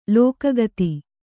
♪ lōka gati